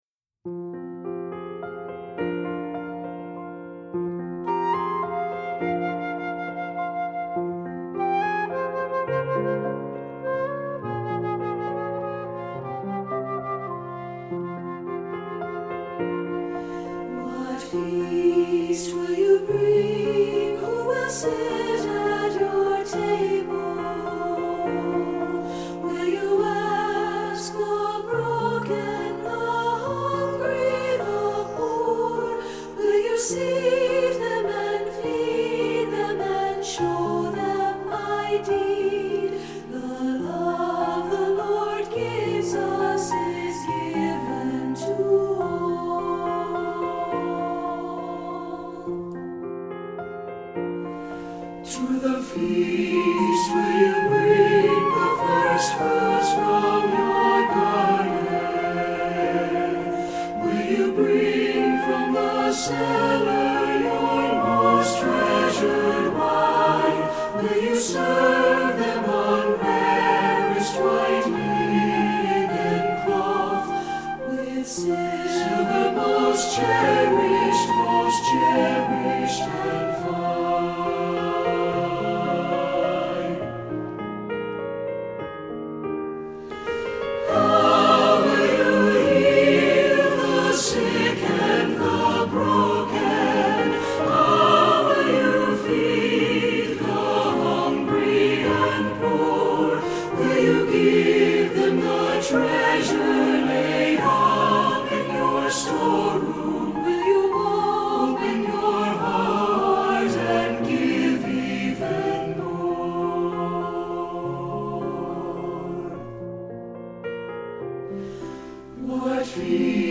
Voicing: SATB and Flute